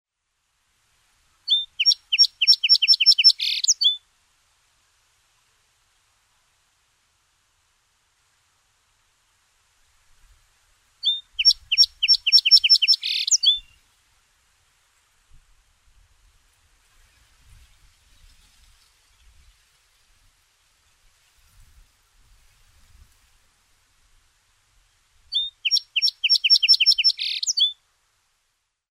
Song Sparrow  Melospiza melodia
Scarborough Marsh, Maine, USA  43° 34' 05.2" N  70° 21' 32.7" W  29 June 2014
Song of descending, clear elements, followed by a trill and a higher frequency element.
Uttered from a lone tree in saltmarsh.
[File name: songsparrowLS112980ecut] Click here for the MP3 file